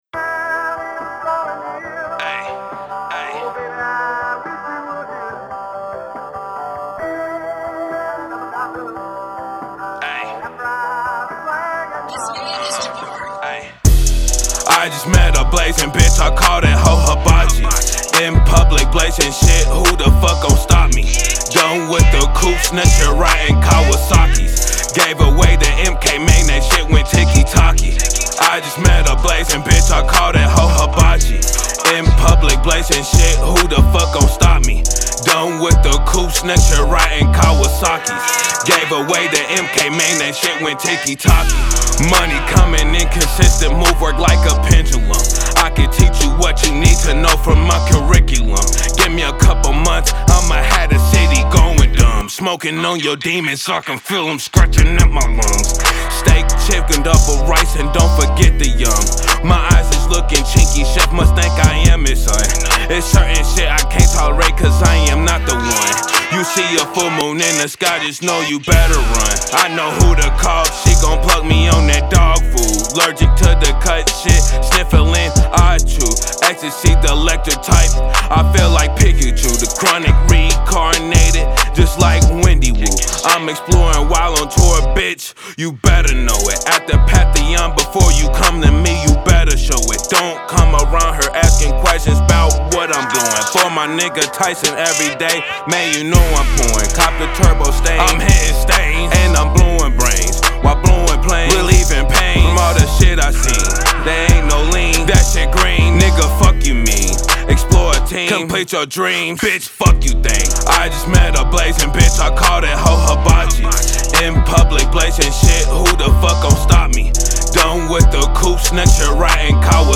Trap